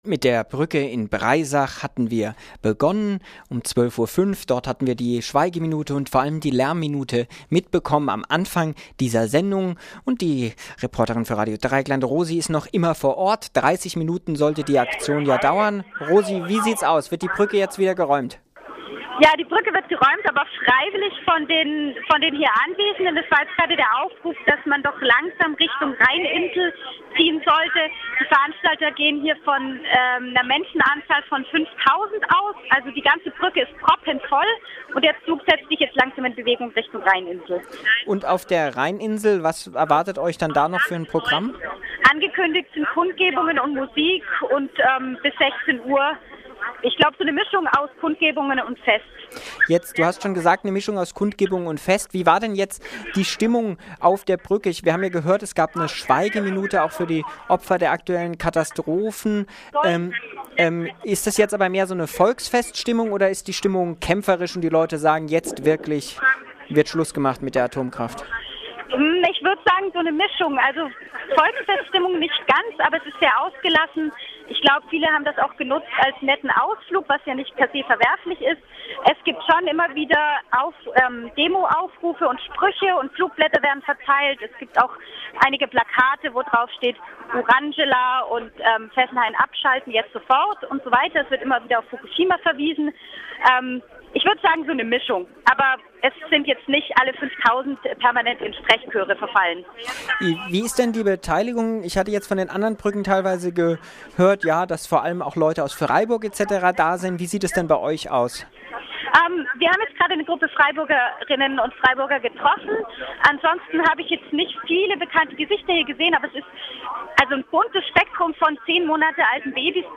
Bei beiden Aktionen waren Reporter/innen von RDL vor Ort. Im Mittagsmagazin wurde live berichtet.
Brückenblockade in Breisach beendet - Bericht [12.40Uhr]